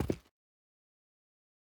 player foot2.wav